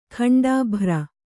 ♪ khaṇḍābhra